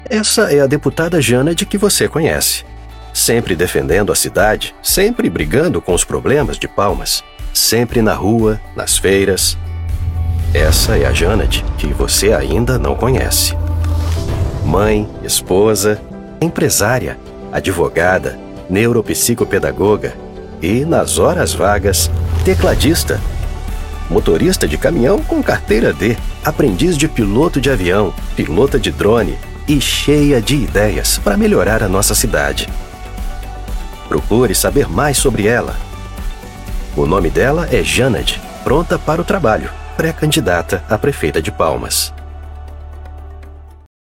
Anuncios políticos
Micrófono TLM 103 Neumann
Preamplificador Inspired Neve 1073
BajoProfundoBajoMuy bajo